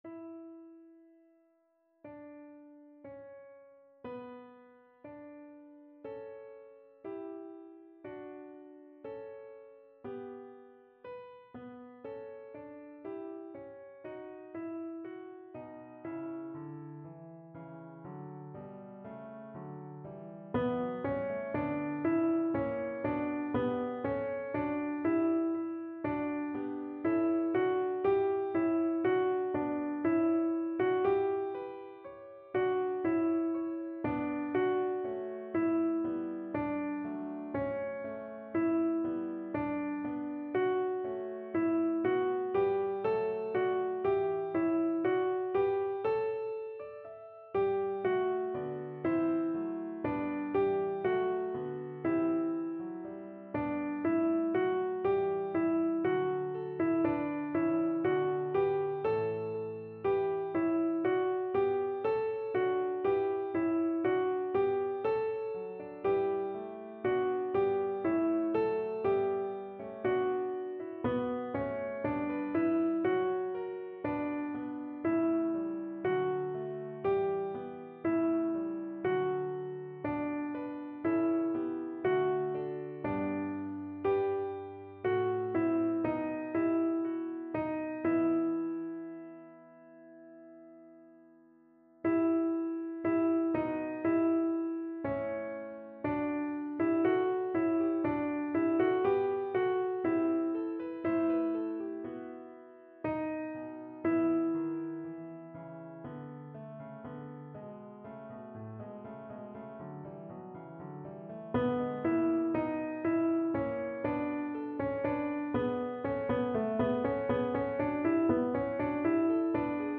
MONTEVERDI Claudio – Messe à 4 voix – Kyrie